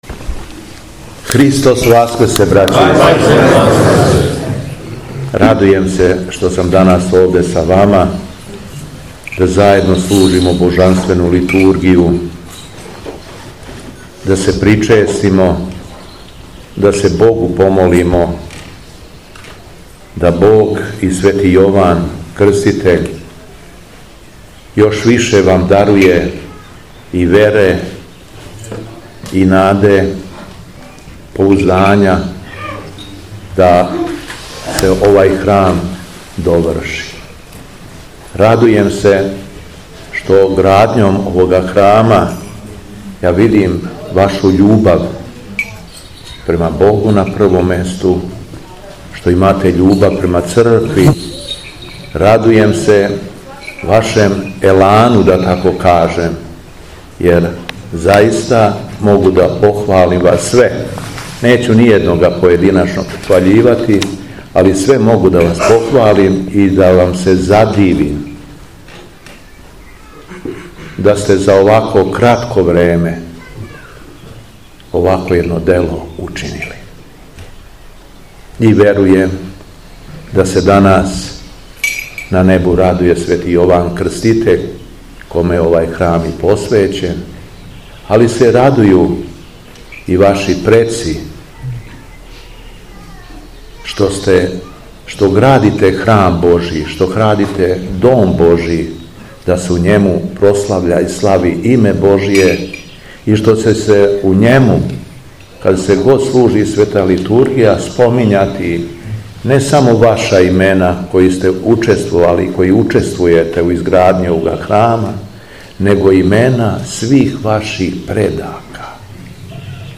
Беседа Његовог Преосвештенства Епископа шумадијског г. Јована
Велики број верника је узео молитвено учешће на Светој Литургији која се по први пут служи у селу Дулене, а епископ се окупљеном народу обратио богонадахнутом беседом.